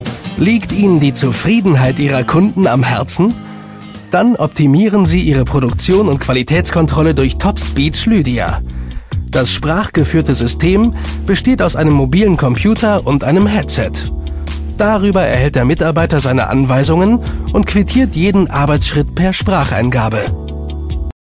Junger, aber erfahrener Sprecher mit besonderer Stimme.
rheinisch
norddeutsch
Sprechprobe: Werbung (Muttersprache):